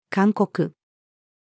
勧告-female.mp3